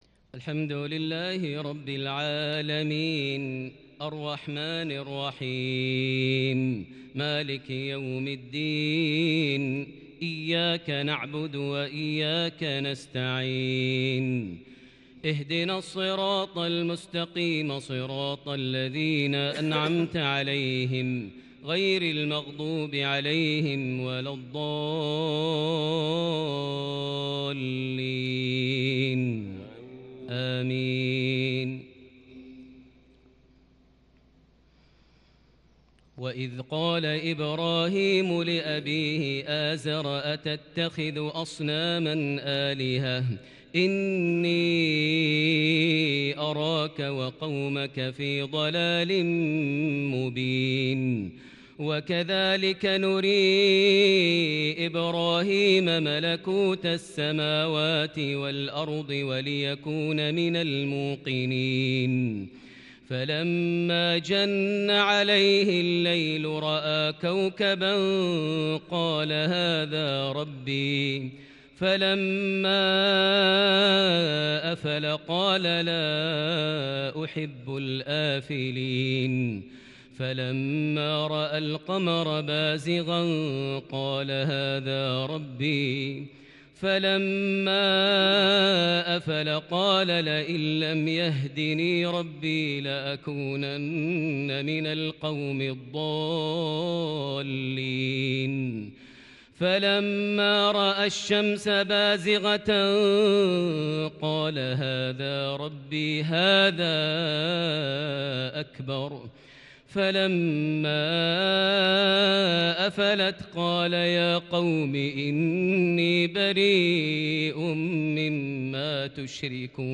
عشائية متألقة فريدة بالكرد من سورة الأنعام (74-90) | 9 جمادى الآخر 1442هـ > 1442 هـ > الفروض - تلاوات ماهر المعيقلي